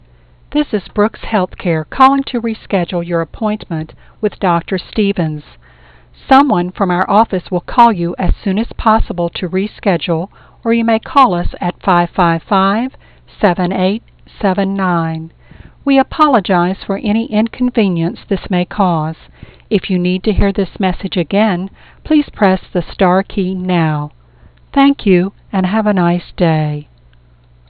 And you'll deliver your messages with professionally recorded, crystal-clear 16 bit sound.